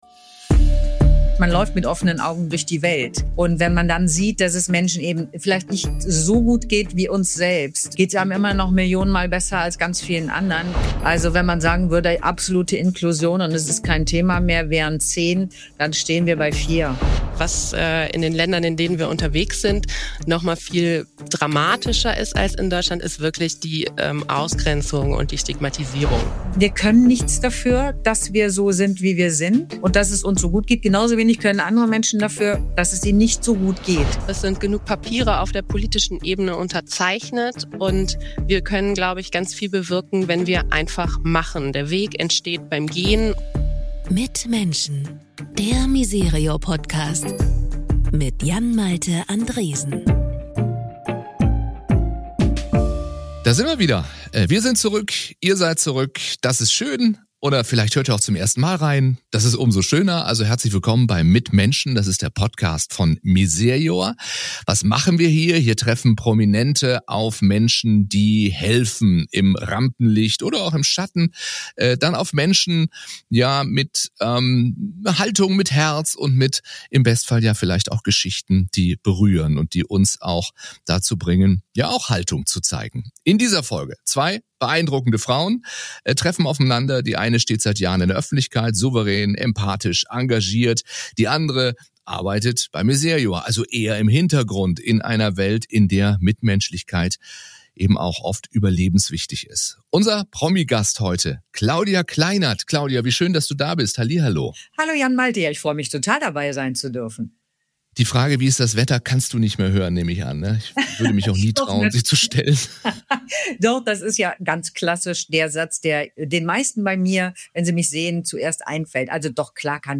Ein Gespräch über Neugier, echte Teilhabe – und darüber, wie viel Engagement zurückgeben kann.